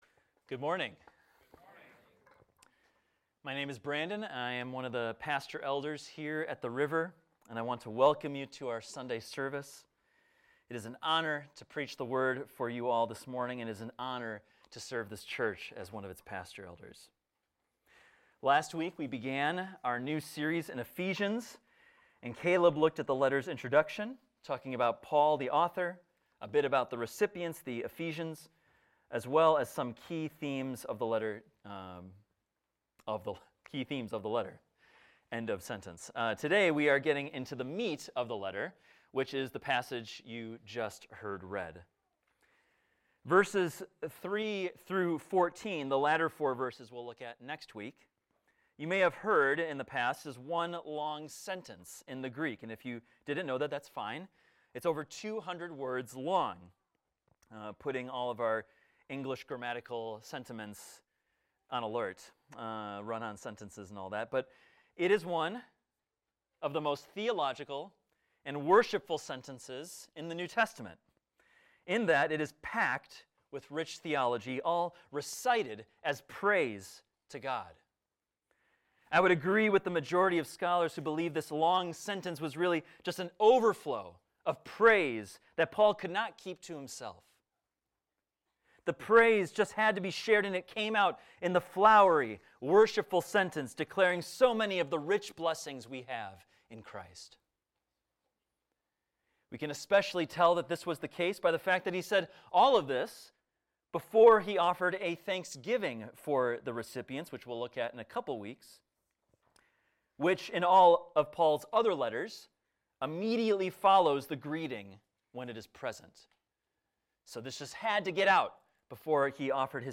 This is a recording of a sermon titled, "Blessings In Christ."